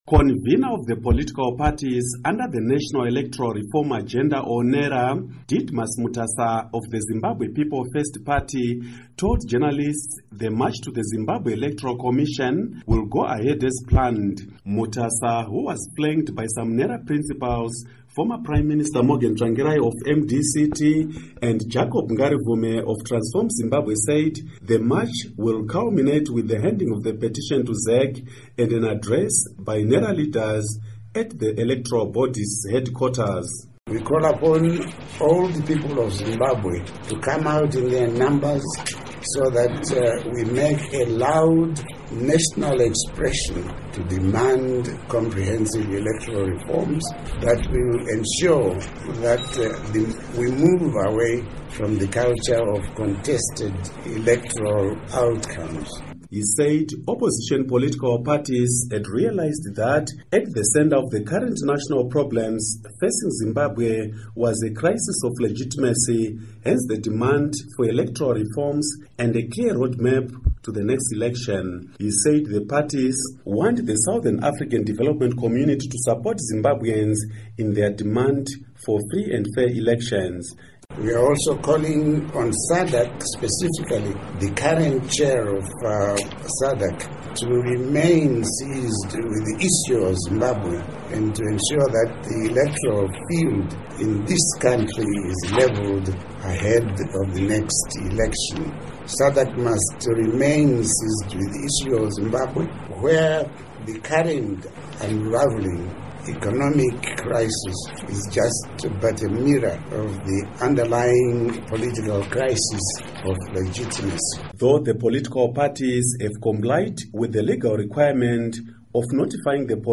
Report On NERA Protest